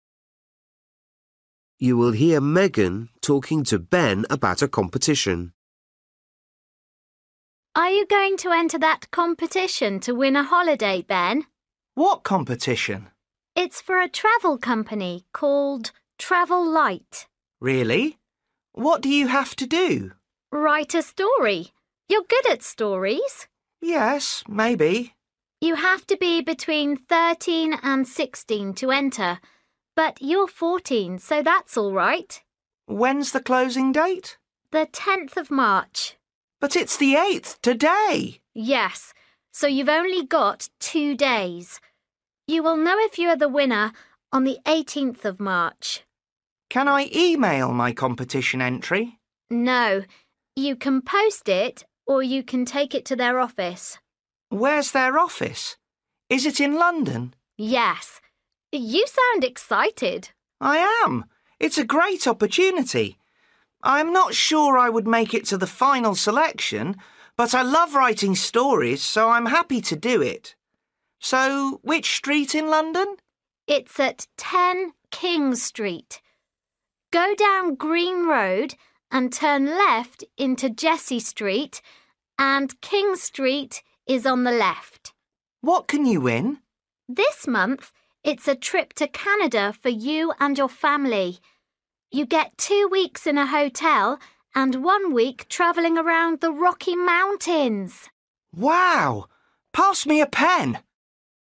Bài tập trắc nghiệm luyện nghe tiếng Anh trình độ sơ trung cấp – Nghe một cuộc trò chuyện dài phần 43